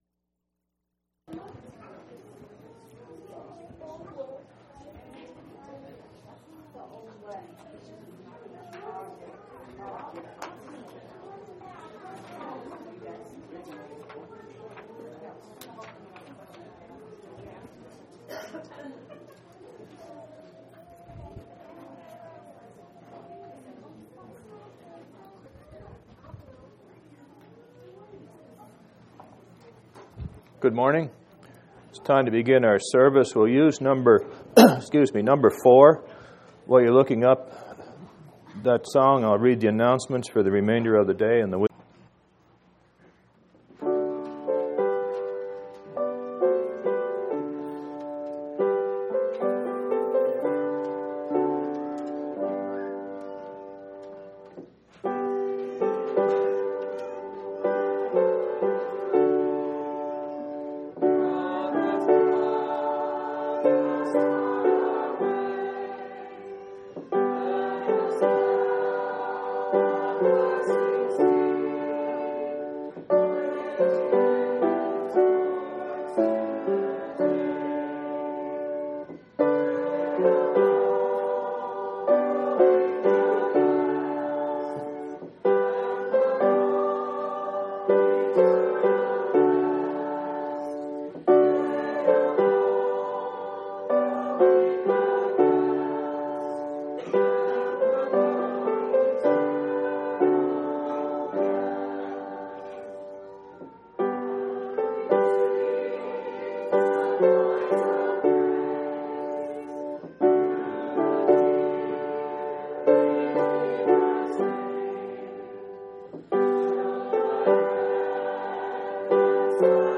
10/19/2003 Location: Phoenix Local Event